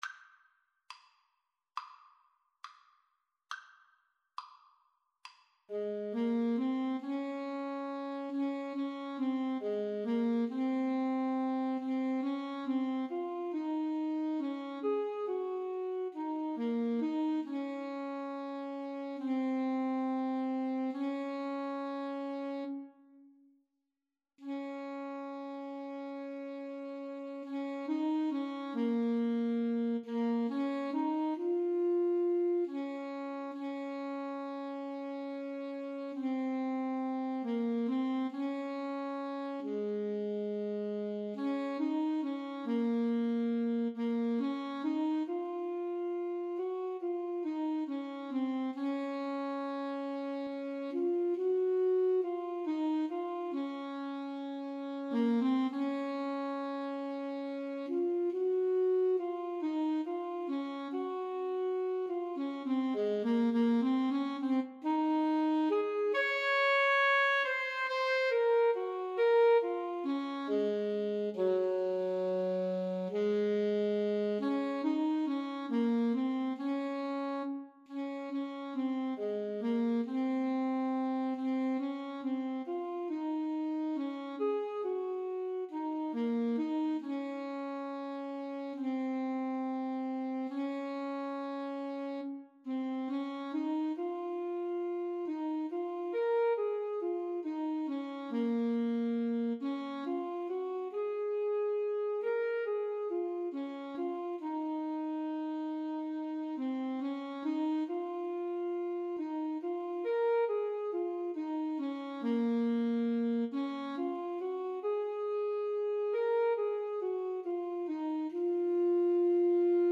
4/4 (View more 4/4 Music)
Espressivo = c. 69
Classical (View more Classical Alto Saxophone Duet Music)